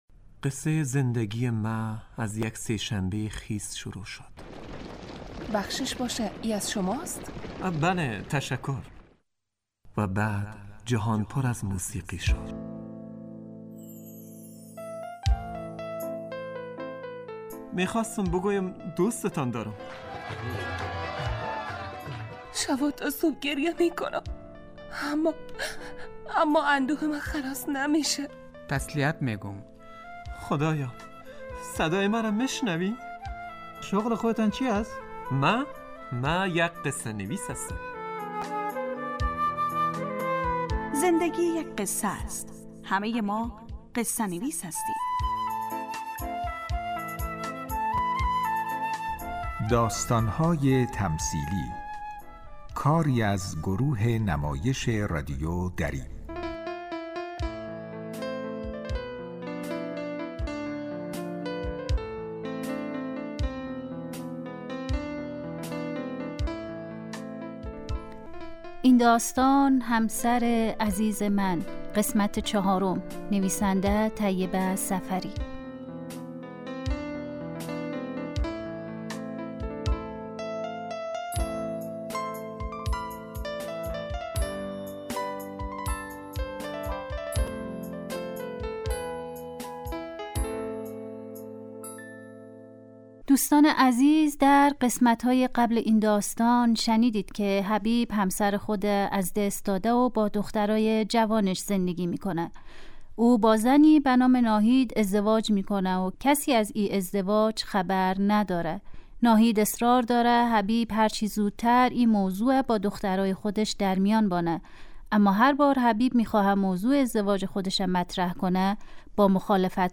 داستان های تمثیلی یک برنامه 15 دقیقه ای در قالب نمایش رادیویی می باشد که همه روزه به جز جمعه ها از رادیو دری پخش می شود موضوع اکثر این نمایش ها پرداختن به...